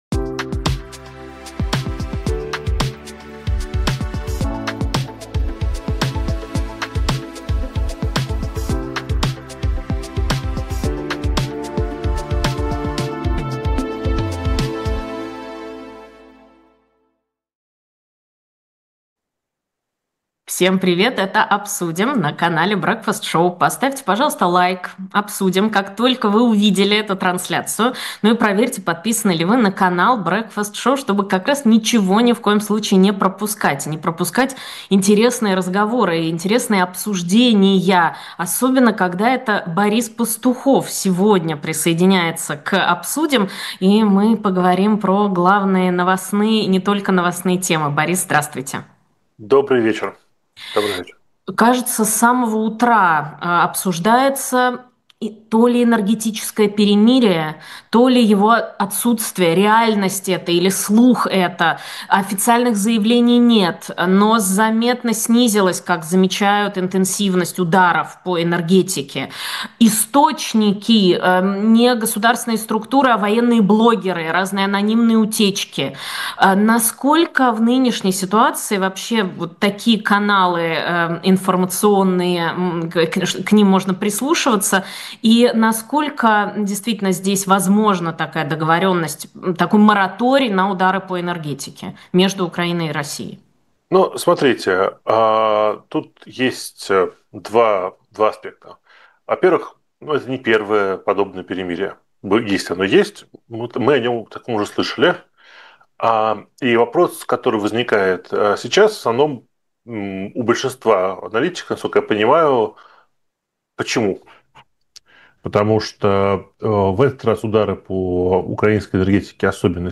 политолог Эфир